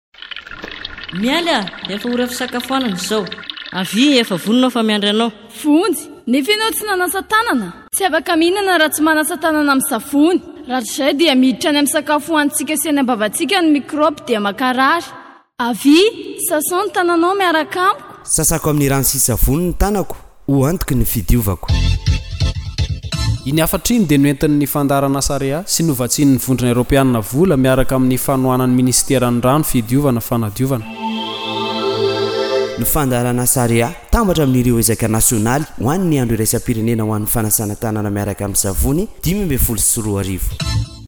Spots radio du programme AREA pour la Journée Mondiale du Lavage des Mains avec du Savon (JMLMS)